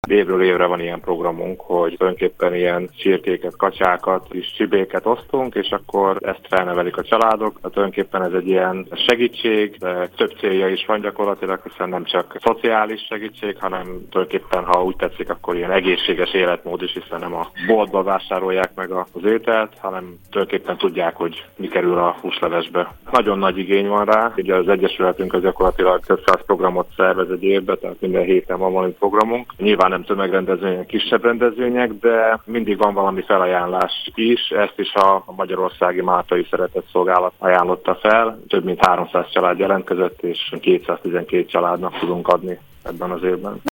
tarpai_hirekbe_riport.mp3